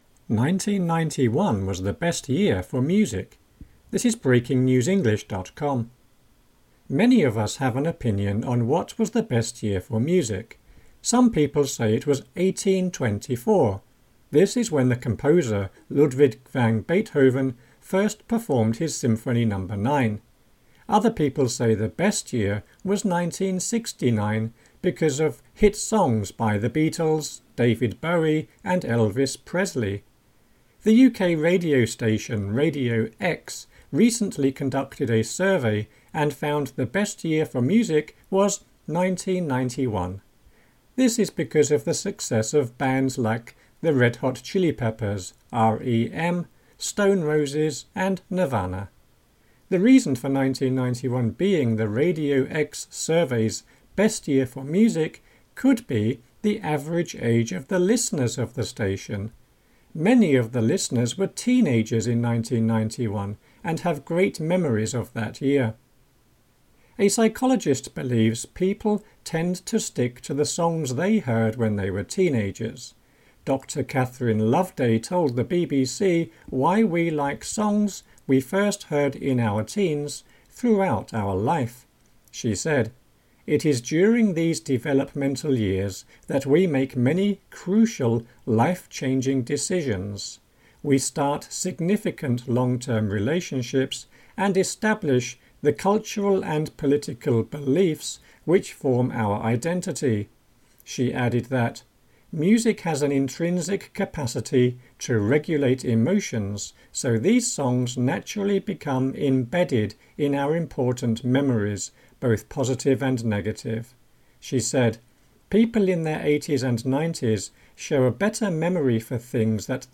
Podéis descargar el audio (podcast) de esta noticia para escucharlo en inglés pinchando en el siguiente icono: